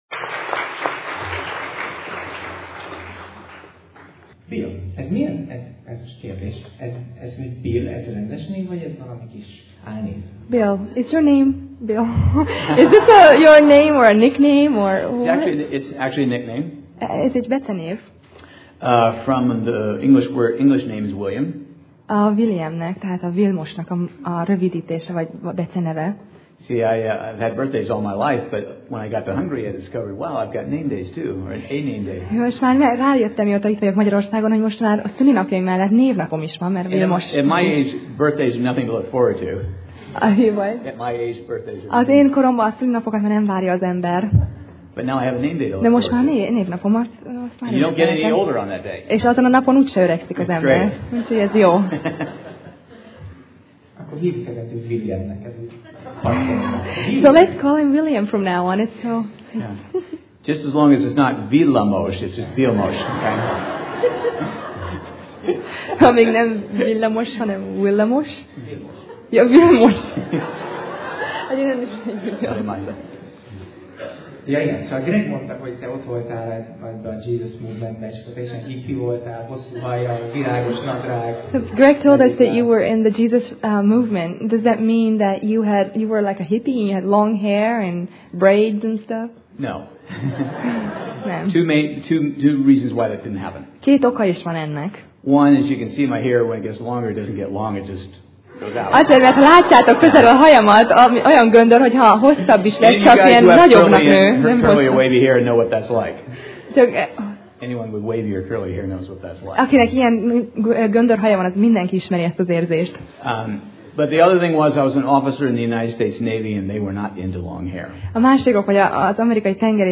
Sorozat: Tematikus tanítás Passage: Lukács (Luke) 18:18-23 Alkalom: Vasárnap Reggel